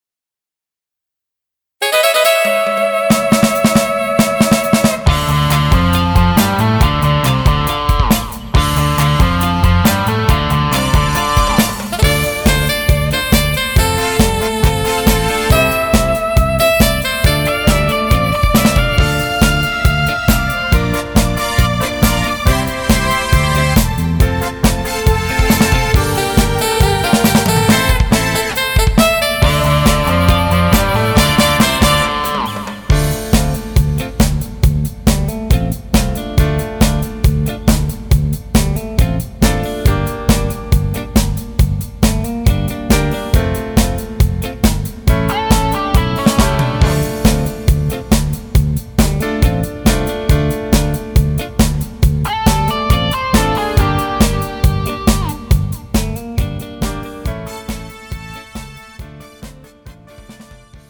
음정 -2키
장르 가요 구분 Pro MR